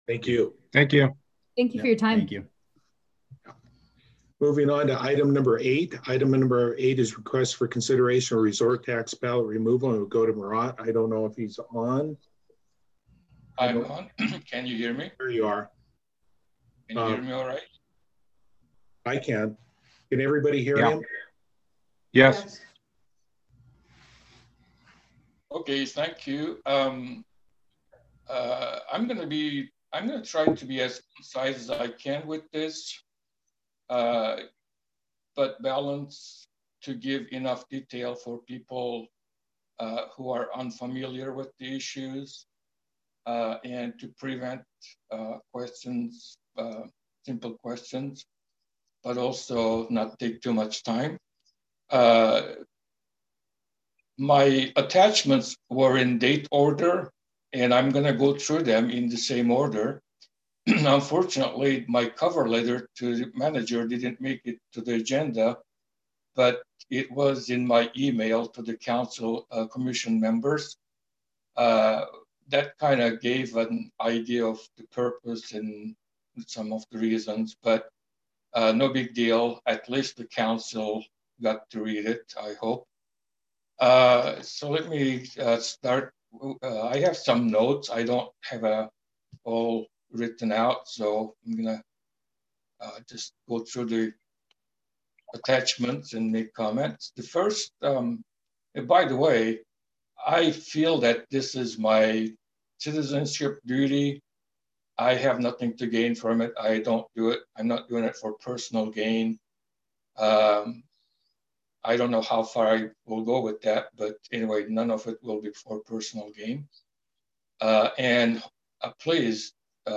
(You can also download from my web site a smaller 47Mb and shorter 51:40 minutes audio file , of slightly lesser sound quality, containing only the extract of my presentation.)